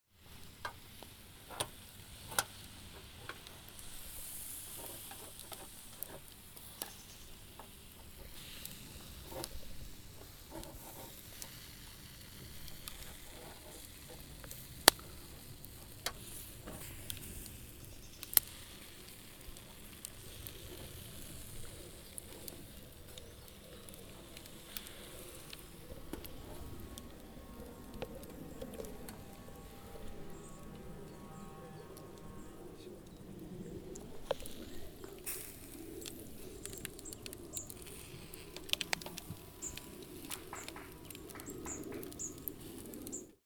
Звуки жарки, гриля
Шепот пламени и шипение шашлыка на огне